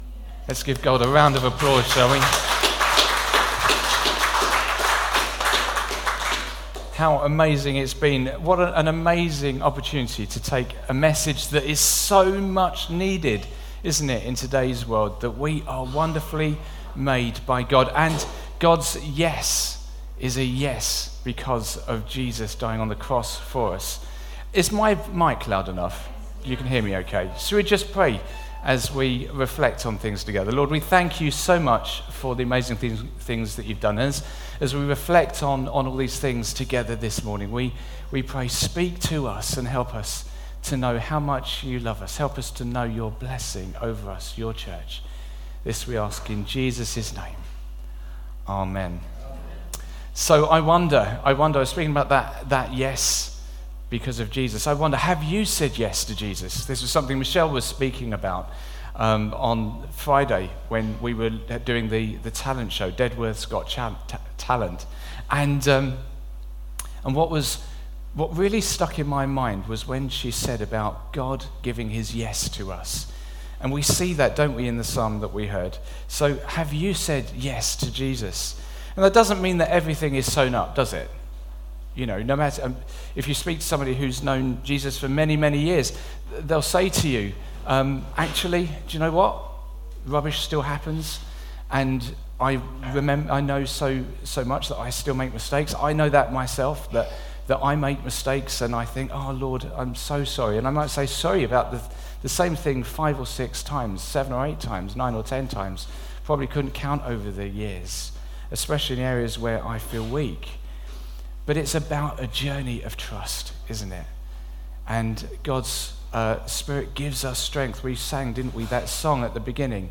Sermon 20th August 2023 10am gathering
We have recorded our talk in case you missed it or want to listen again.